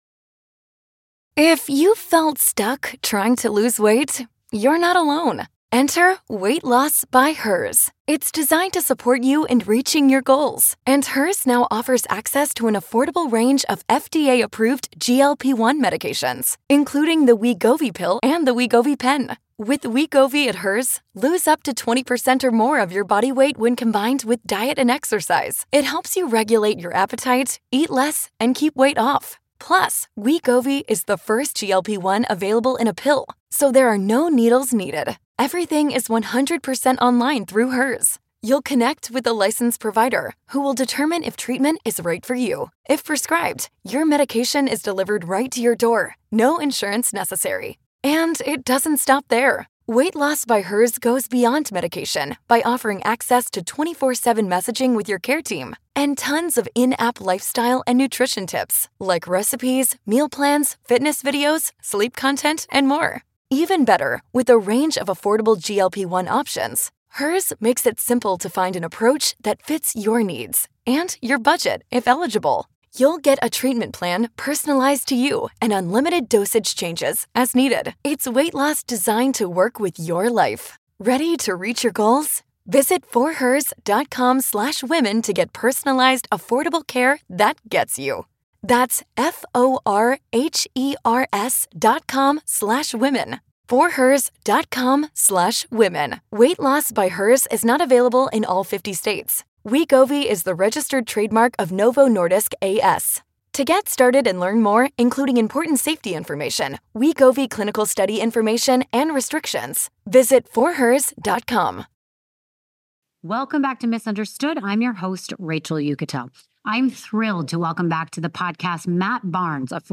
Prepare for a deep, insightful, and unfiltered conversation with Matt Barnes.